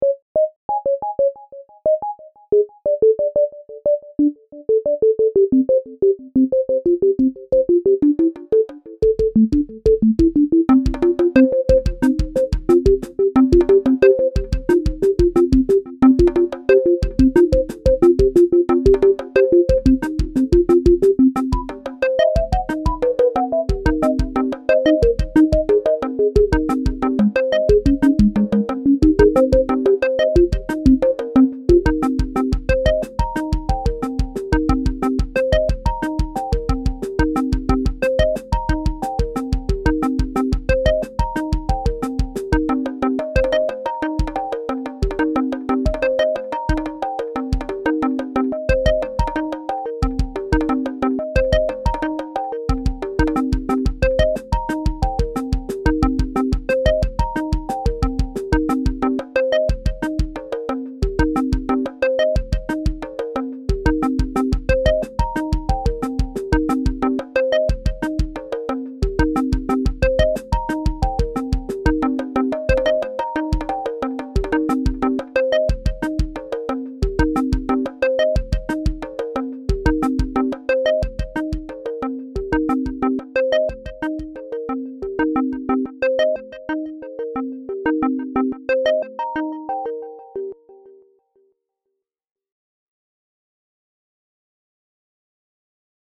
Pieza de techno ambiental
pieza tecno melodía repetitivo sintetizador